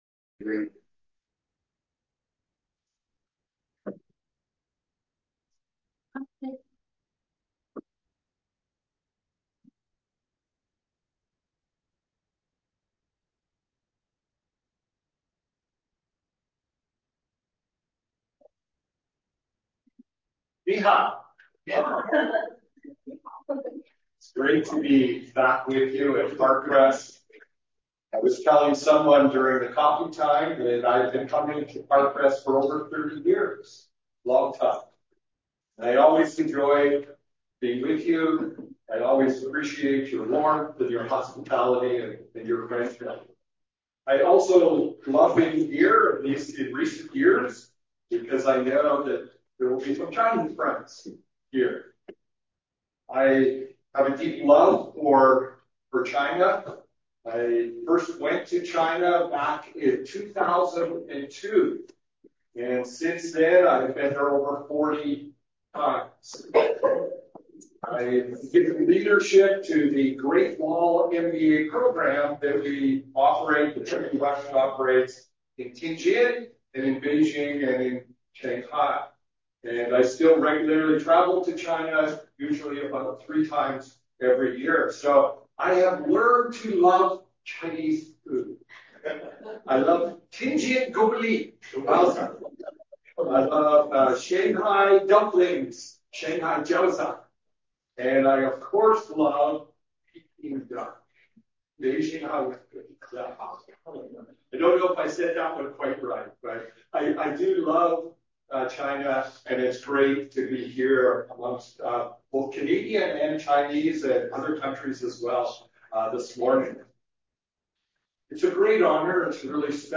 Miscellaneous Sermons – Parkcrest Bible Chapel